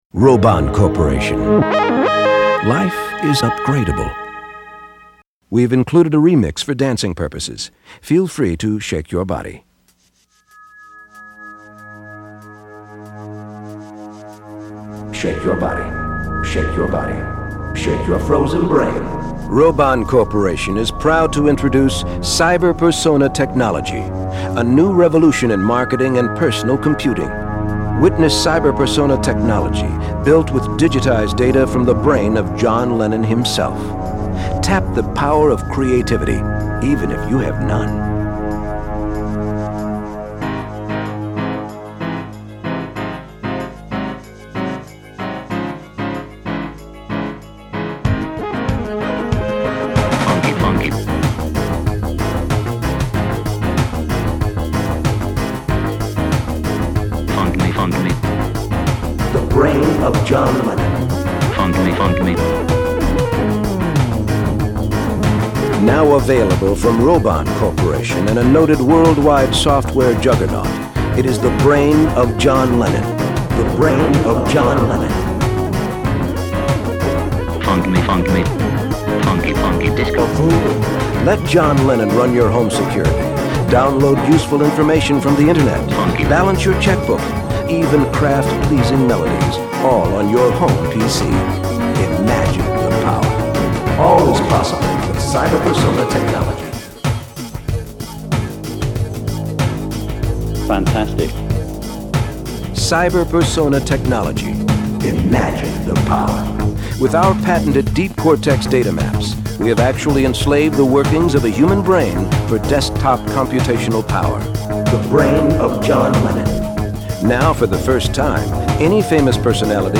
I think this was the first time I attempted “beats.”